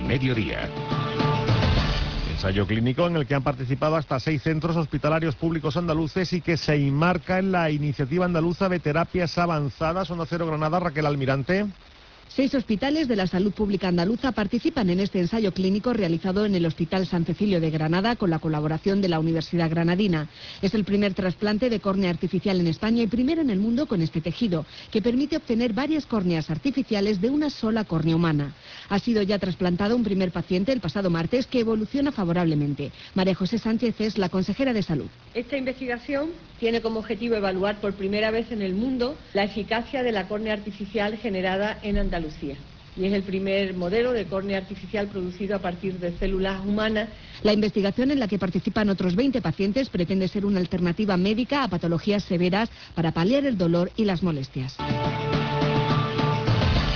AUDIO: Onda Cero Andalucía – NOTICIAS MEDIODIA ANDALUCIA: PRIMER IMPLANTE CON TEJIDO ARTIFICIAL DE CORNEA
DECL. MARIA JOSE SANCHEZ, CONSEJERA ANDALUZA DE SALUD.